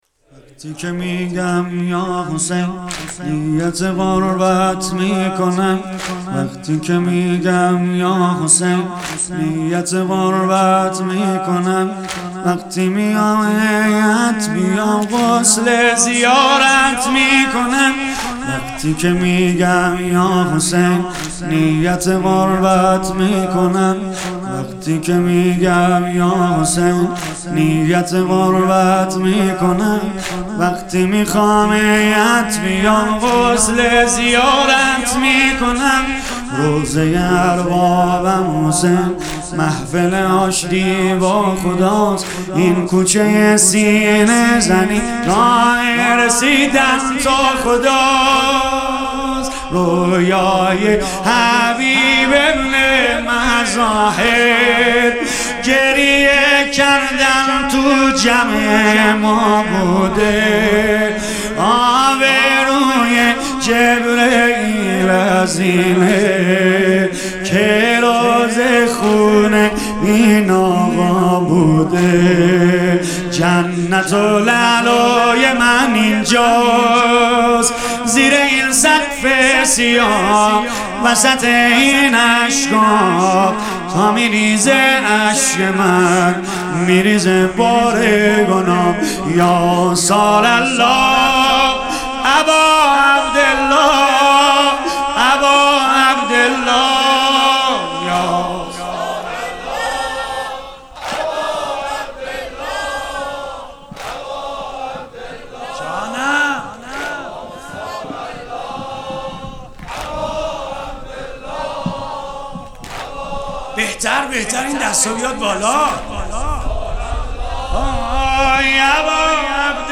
واحد | وقتی که میگم یاحسین نیت غربت می کنم | سید رضا نریمانی
شب سوم محرم ۹۹ - هیئت فدائیان حسین
سید رضا نریمانی محرم99 مداحی جدید واحد زیبا